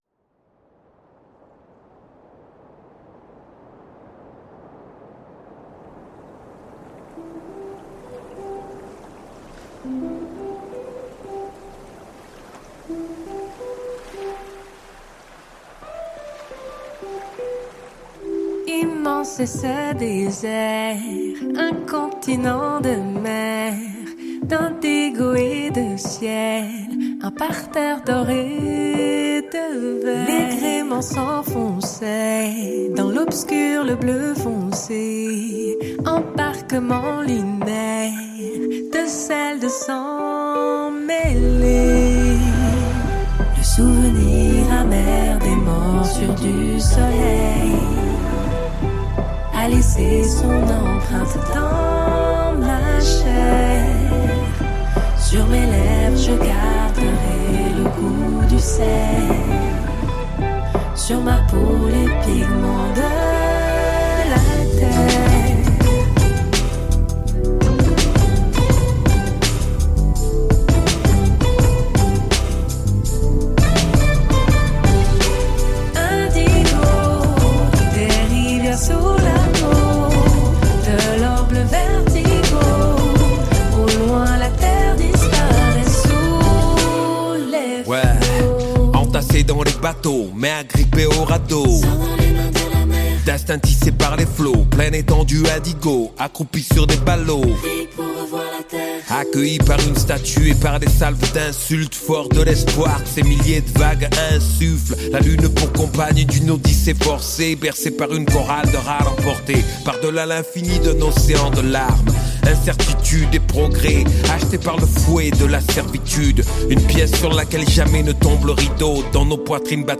Ce morceau soul et rap, chanté en français
scratches